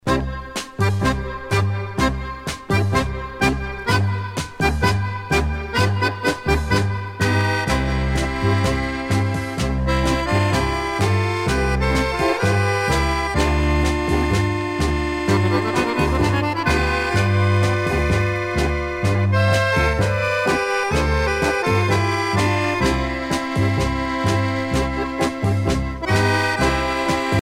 danse : tango musette
Pièce musicale éditée